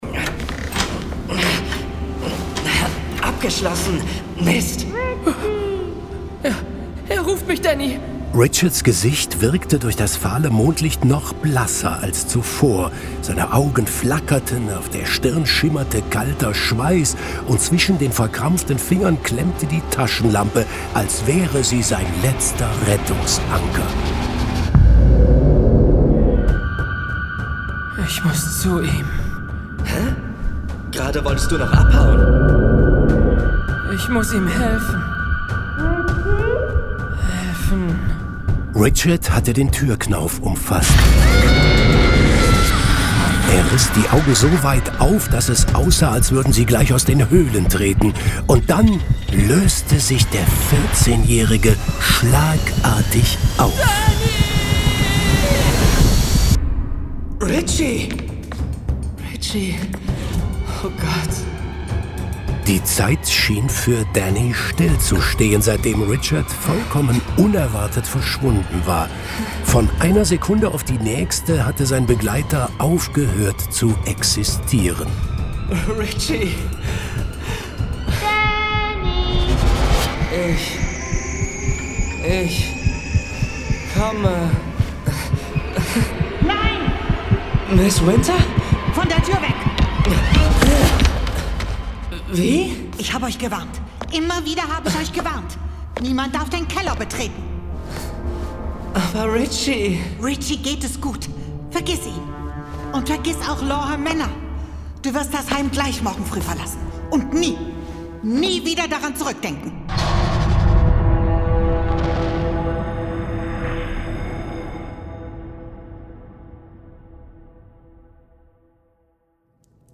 Professor Zamorra - Folge 8 Para-Geister. Hörspiel.